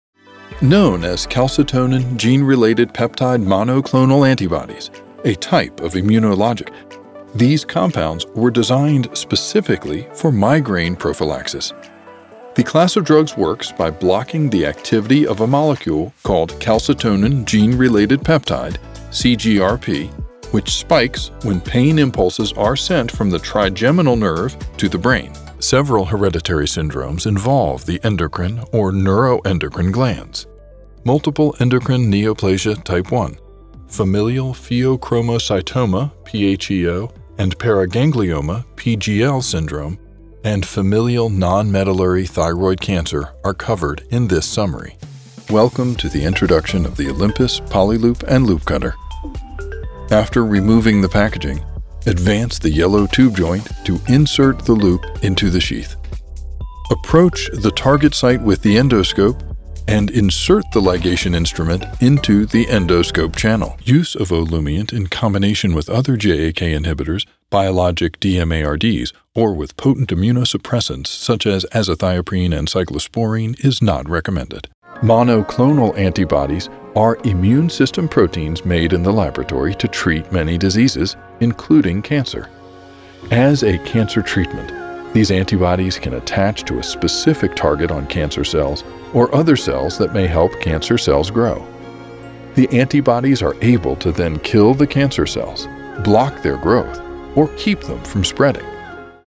Bourbon and blue jeans, a smooth, comfortable, confident voiceover.
English - USA and Canada
Middle Aged